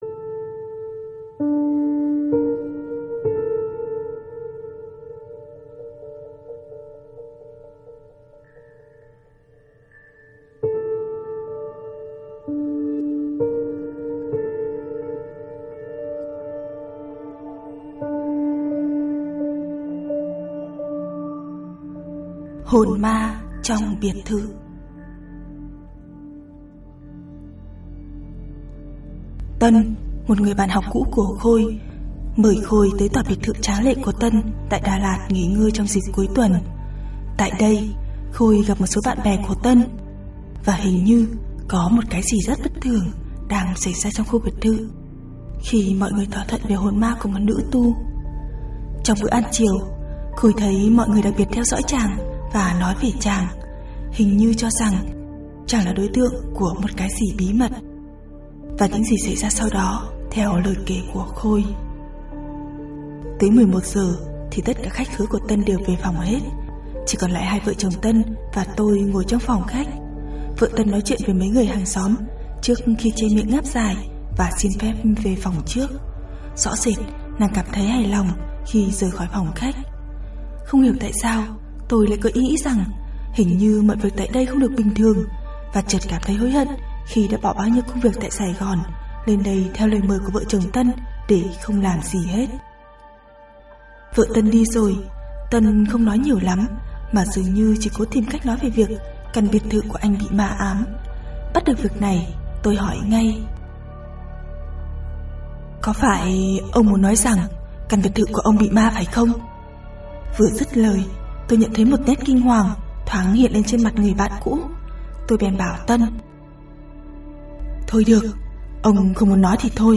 Home Truyện Ma Audio Hồn Ma Trong Biệt Thự 23.07.2014 Admin Hồn Ma Trong Biệt Thự Tân, một người bạn học cũ của Khôi, mời Khôi tới toà biệt thự tráng lệ của Tân tại Đà Lạt nghỉ ngơi trong dịp cuối tuần.